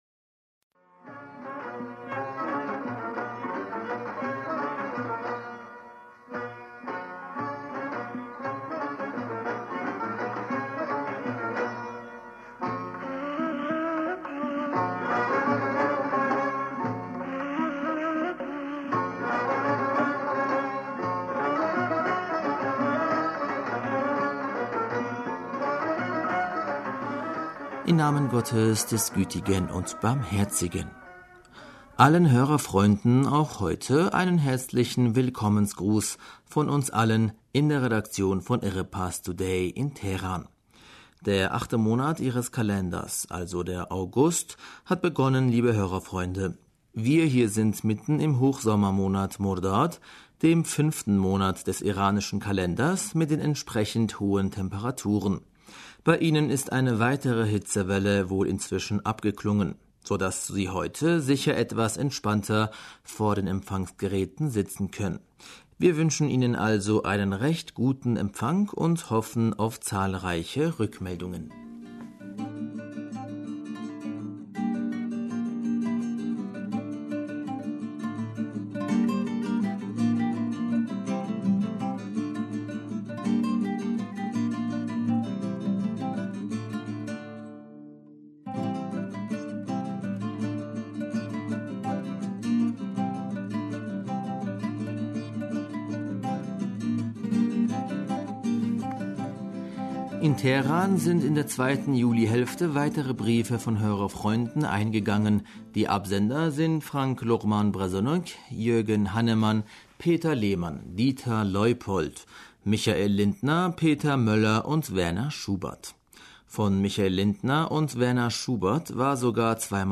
Hörerpostsendung am 4. August 2019